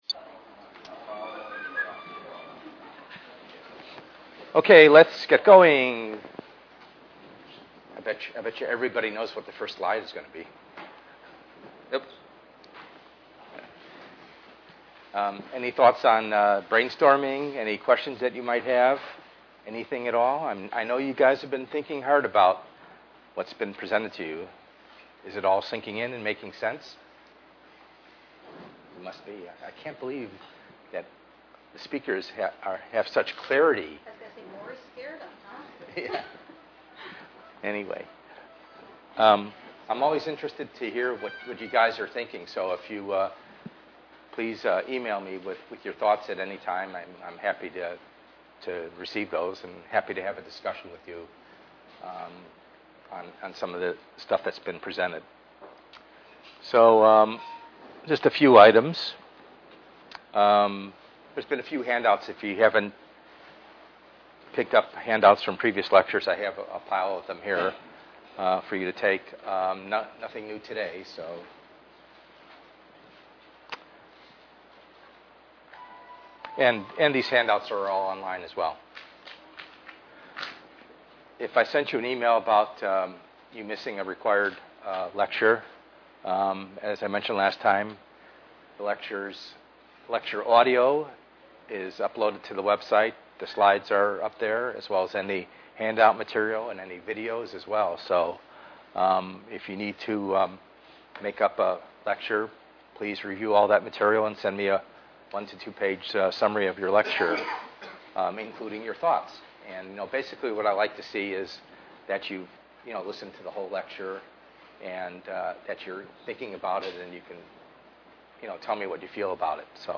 ENGR110/210: Perspectives in Assistive Technology - Lecture 2b